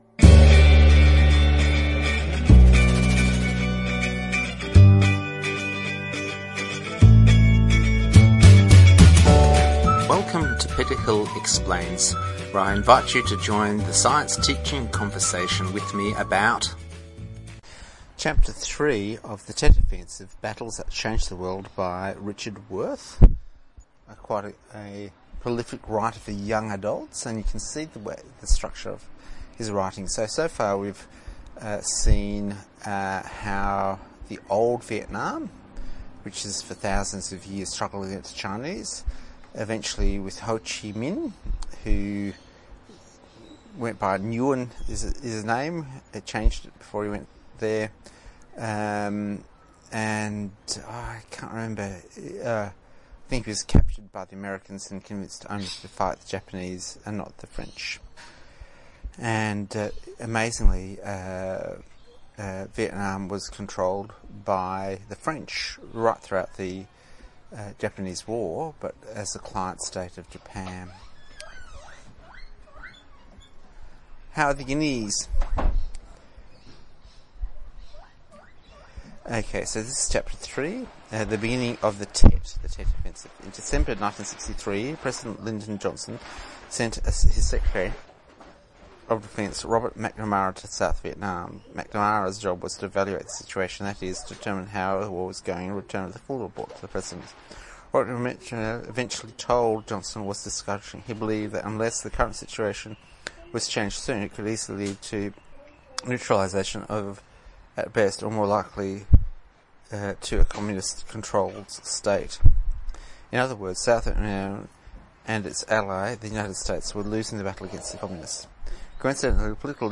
Reading a short explanation of the Battle that the North Vietnamese lost but won the war MP4 recording MP3 recording ...